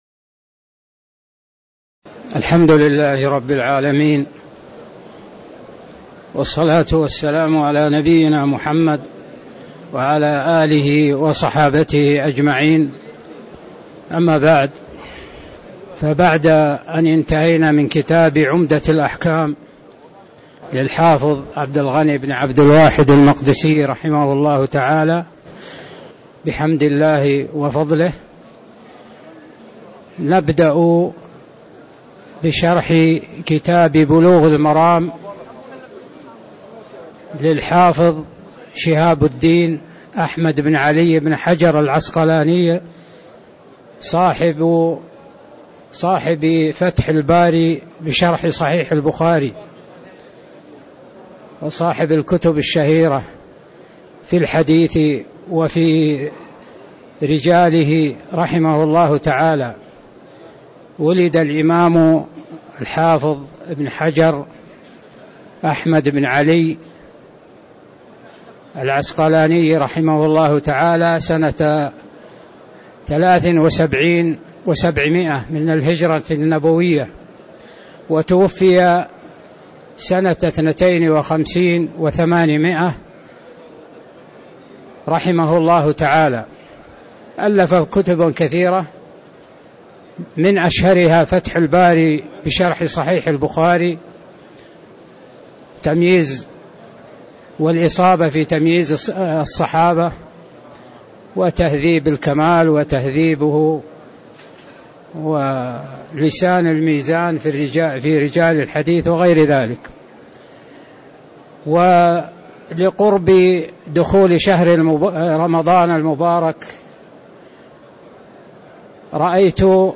تاريخ النشر ٢٤ شعبان ١٤٣٧ هـ المكان: المسجد النبوي الشيخ